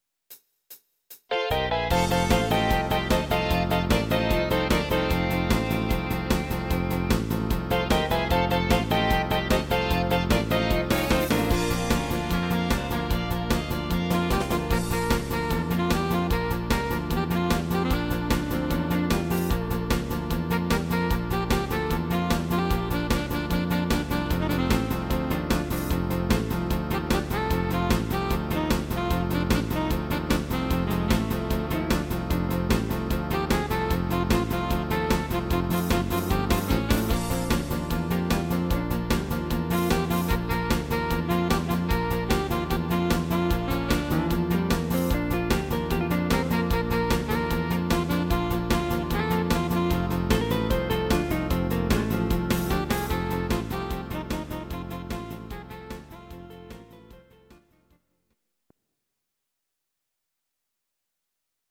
Audio Recordings based on Midi-files
Country, 1970s